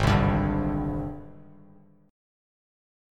G#M#11 chord